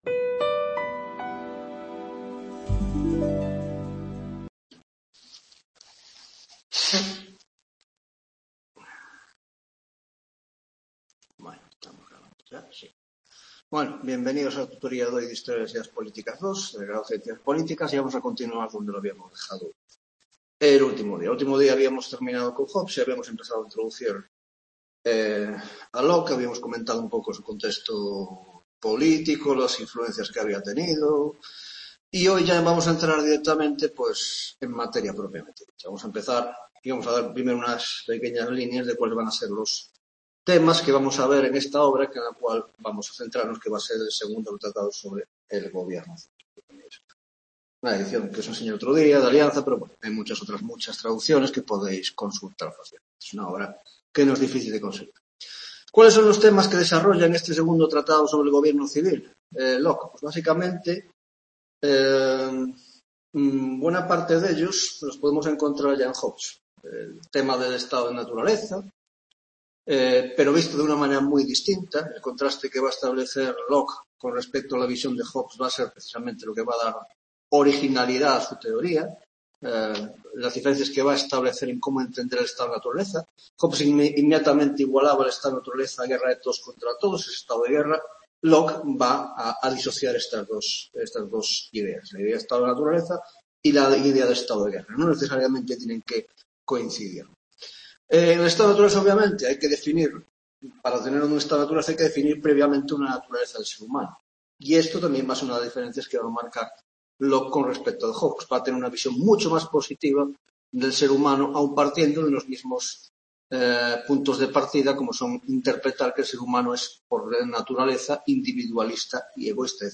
3ª Tutoría de Historia de las Ideas Políticas 2 (Grado de Ciencias Políticas) - John Locke y los orígenes del liberalismo (2ª parte)